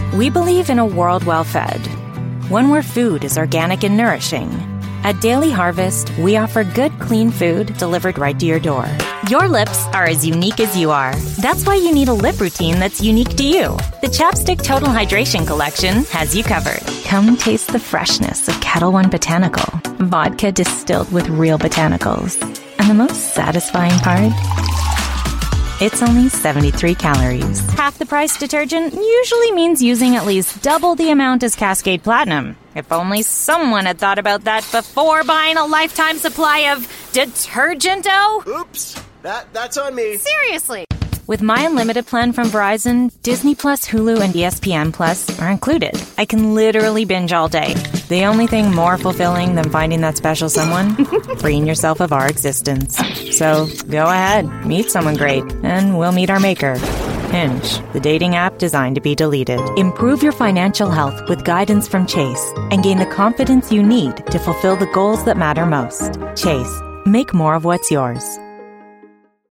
Englisch (Kanadisch)
Glatt
Konversation
Freundlich